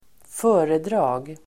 Uttal: [²f'ö:redra:g]